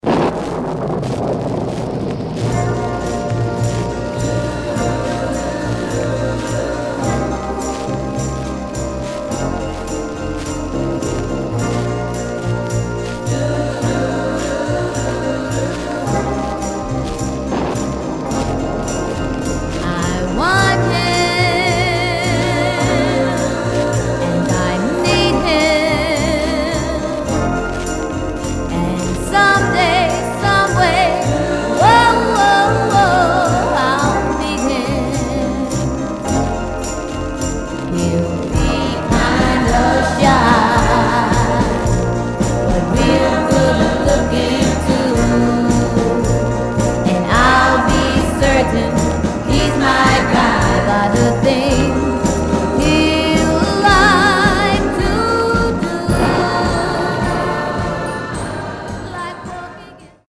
Stereo/mono Mono
Girl Groups